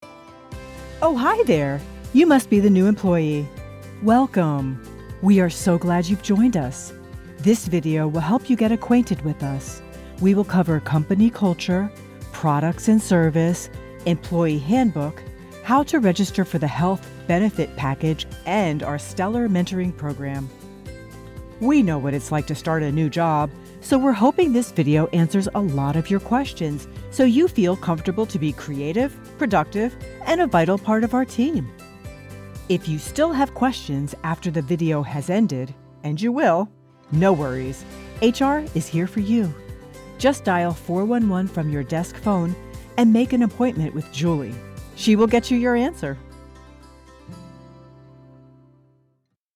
onboarding, relatable, conversational, upbeat, friendly
Engaging , confident, conversational, and empathetic.
Onboarding demomwith music multitrack_mixdown.mp3